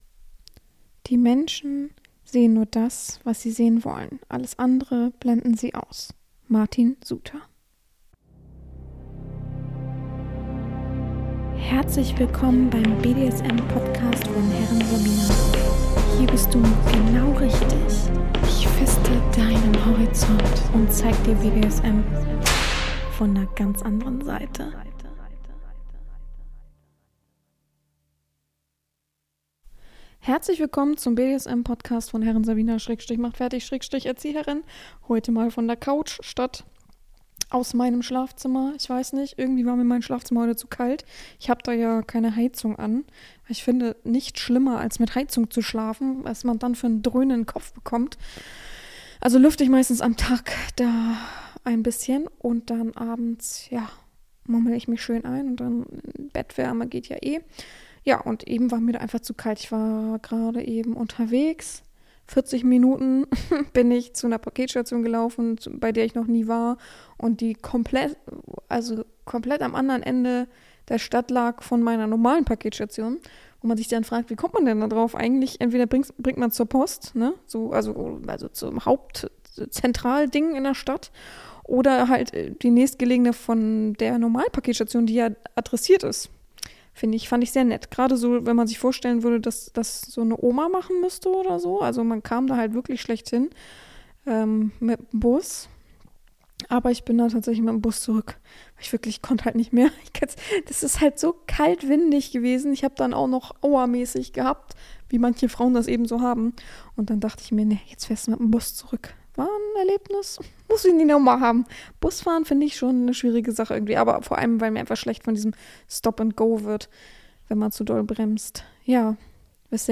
Chaque podcast réunira : un libraire, un journaliste ou influenceur, un éditeur.